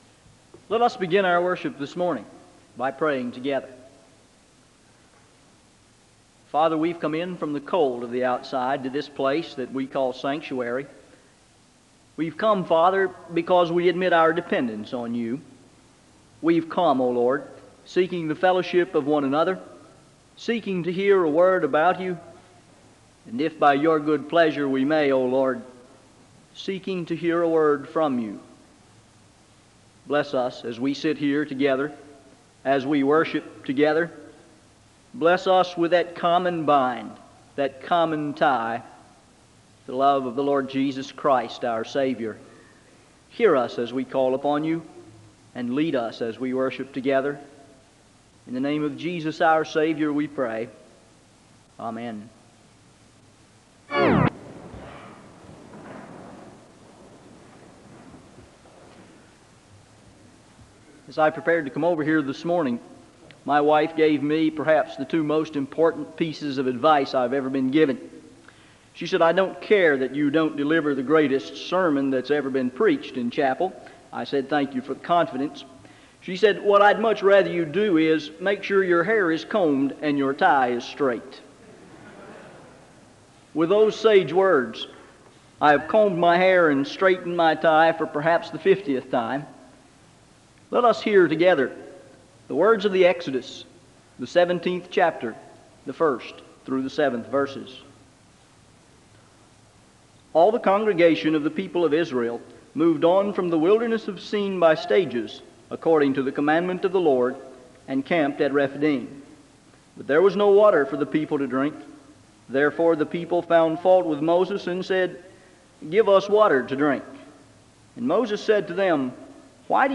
The sermon opens with prayer (0:00-0:52).
Speaker closes in prayer (17:54-18:58).